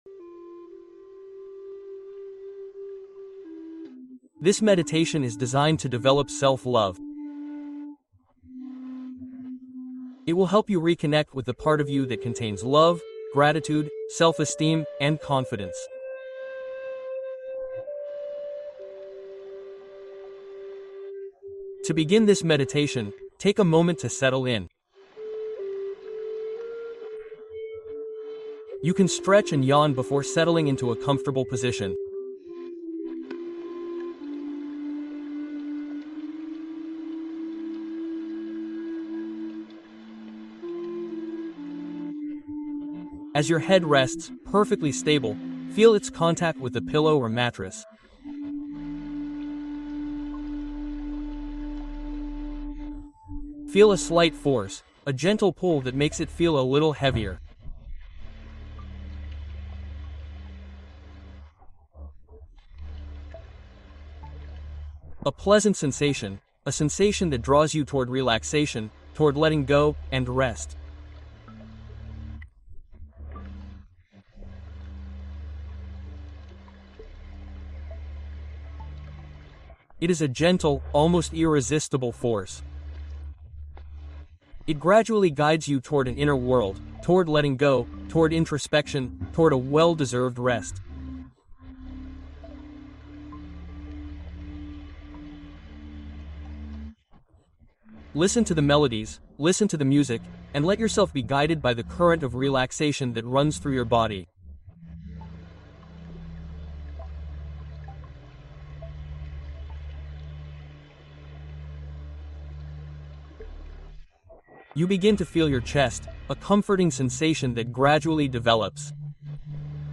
Découvrez Comment Enfin Vous Aimer Vous-Même (Méditation Libératrice)